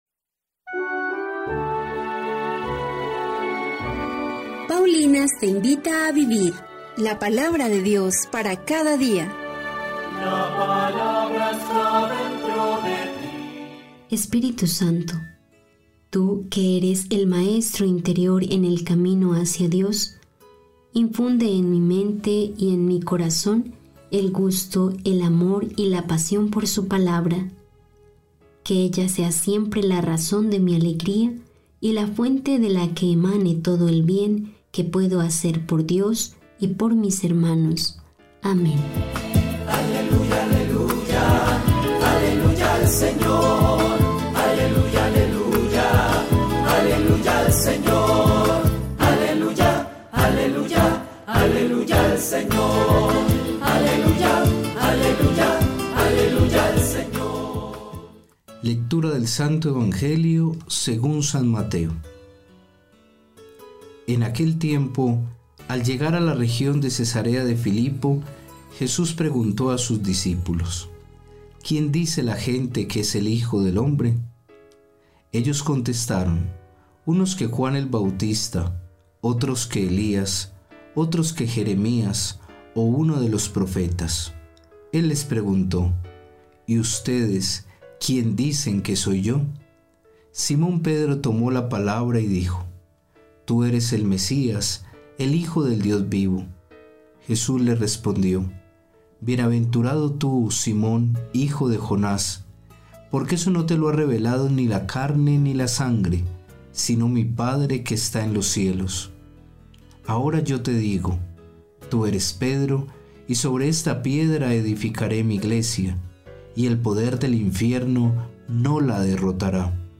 Lectura del libro de los Números 12, 1-13